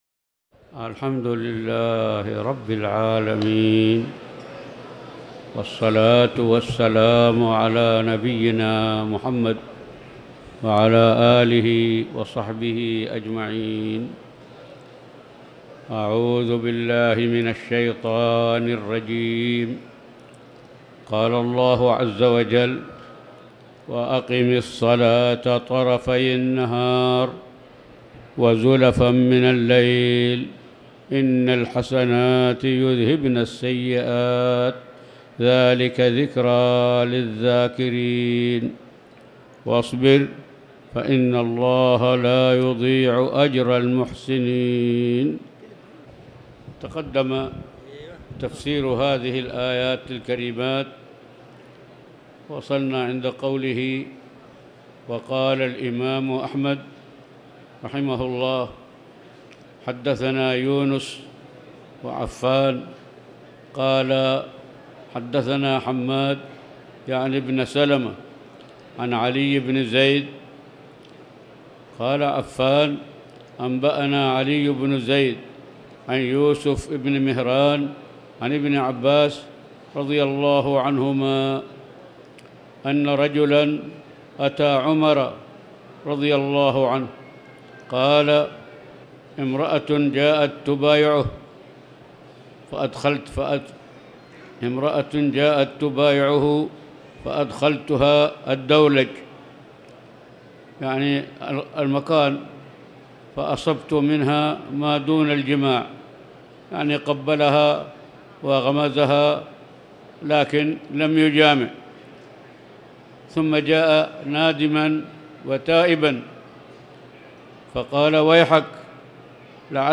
تاريخ النشر ٣ صفر ١٤٤٠ هـ المكان: المسجد الحرام الشيخ